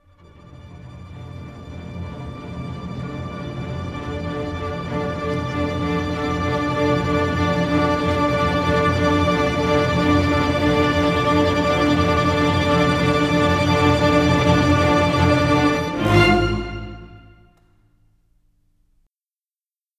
Cinematic 27 Strings 04.wav